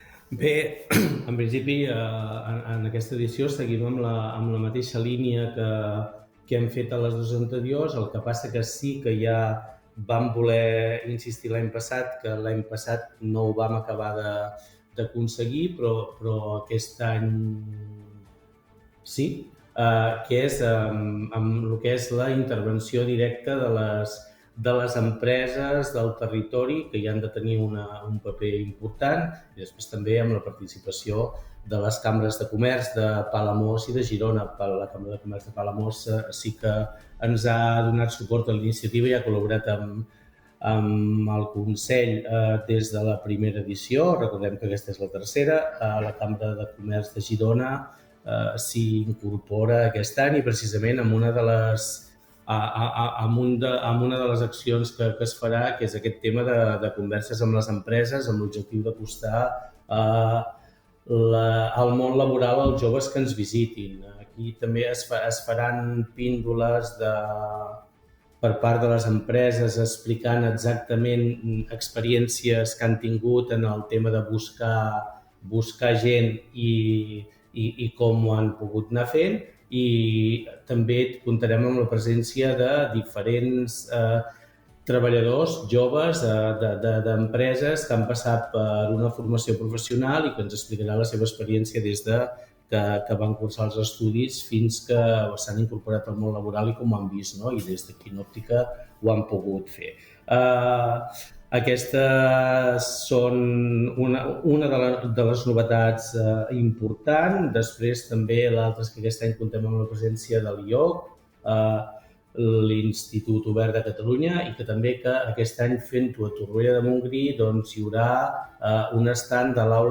Per això ens ha visitat el vicepresident primer del Consell Comarcal del Baix Empordà, Enric Marquès, al Supermatí.